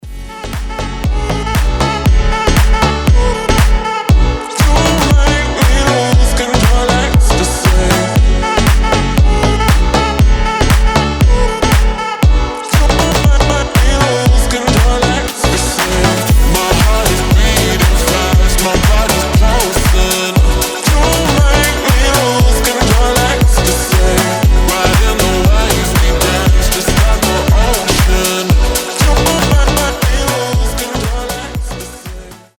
deep house
Саксофон
organ house
Зажигательно и с саксофоном